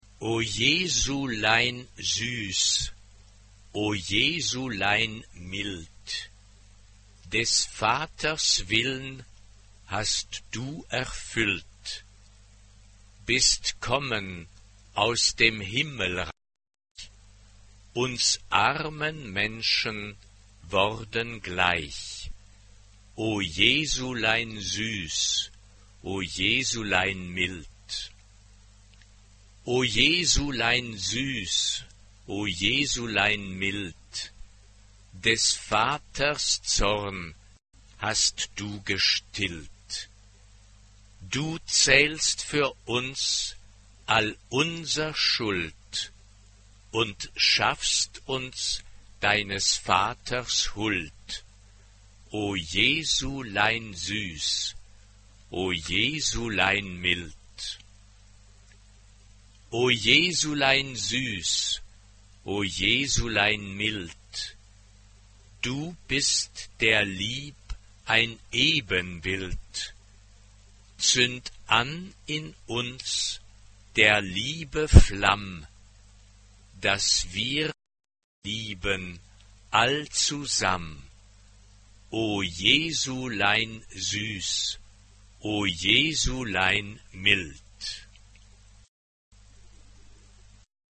TTBB (4 voix égales d'hommes).
Baroque. Choral.
Genre-Style-Forme : Sacré ; Baroque ; Choral Caractère de la pièce : allegretto